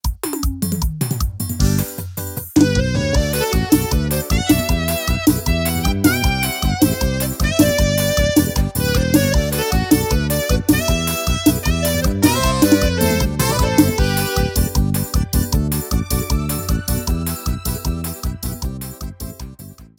• Demonstrativo Arrasta-pé:
• São todos gravados em Estúdio Profissional, Qualidade 100%